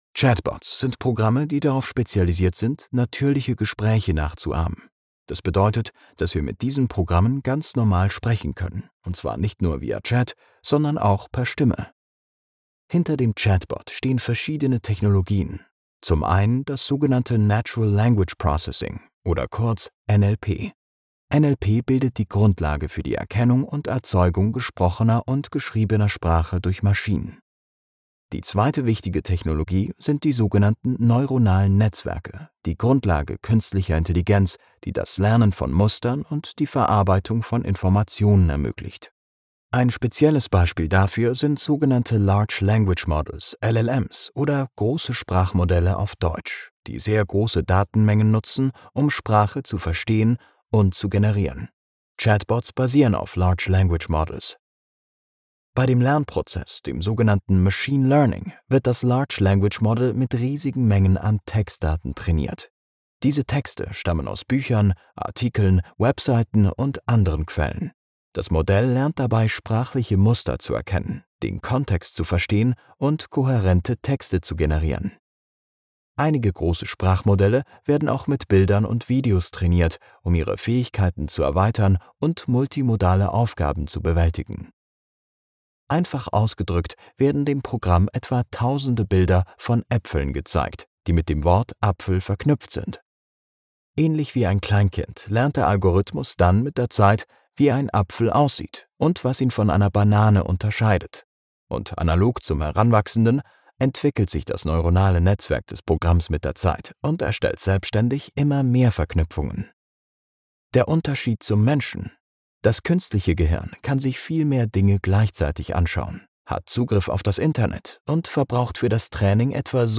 Natural, Reliable, Friendly, Commercial, Warm
E-learning